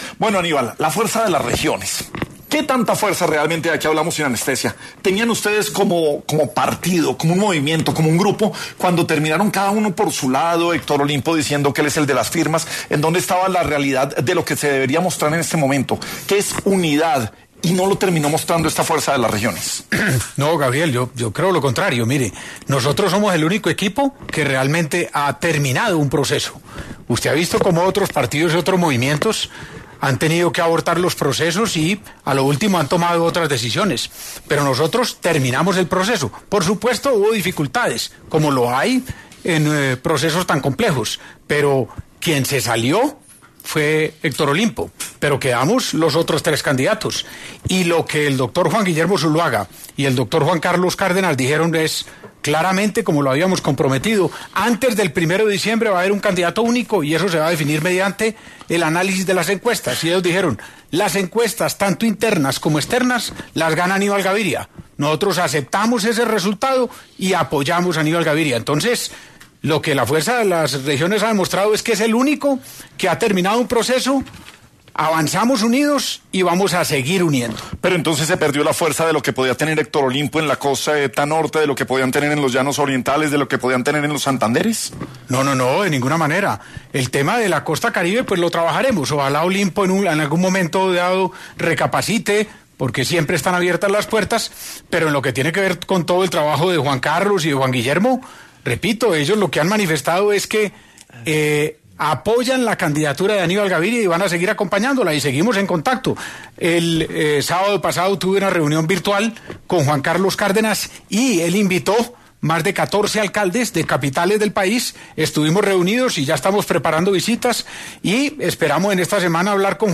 En este contexto, Gaviria pasó por los micrófonos de ‘La Luciérnaga’, para hablar ‘Sin Anestesia’ del proceso de elección del candidato de ‘Fuerza de las Regiones’ y su relación actual con Héctor Olimpo.